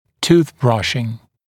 [tuːθ ‘brʌʃɪŋ][ту:с ‘брашин]чистка зубов щеткой